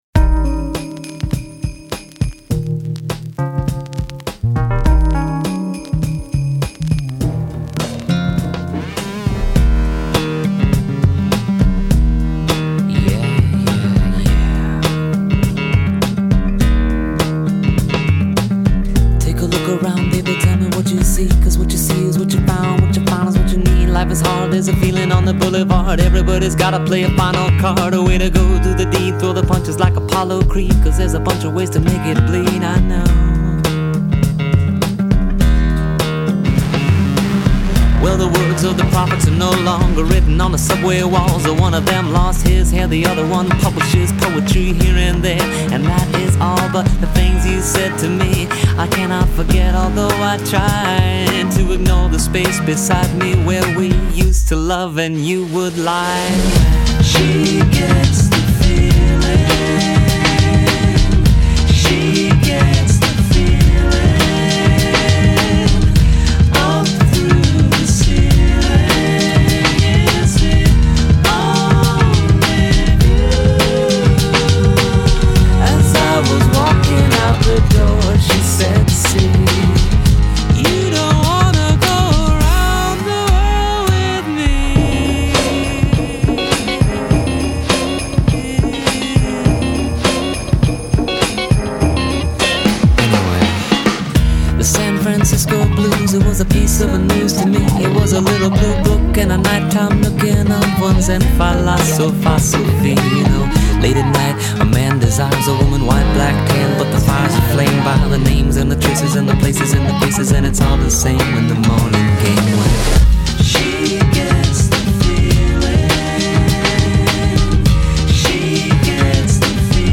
is an excellent collection of smart, contemporary pop songs.
brimming with great hooks, clever lyrics